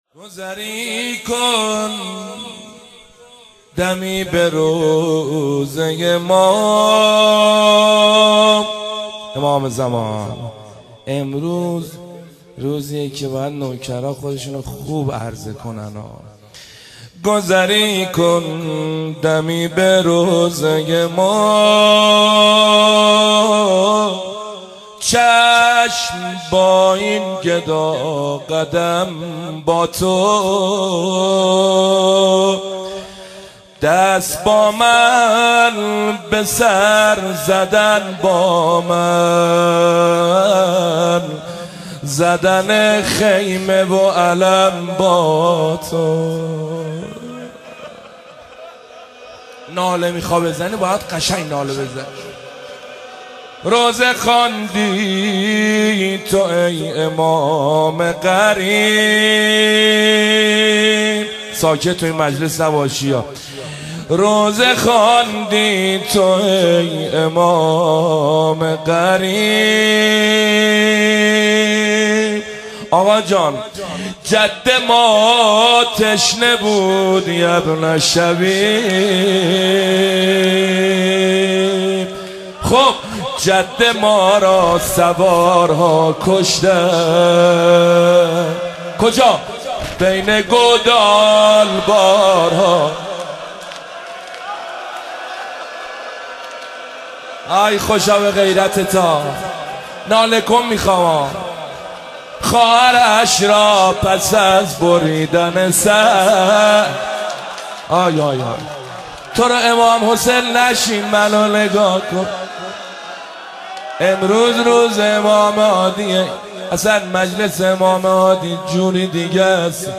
مداحی
بمناسبت شهادت امام هادی (ع)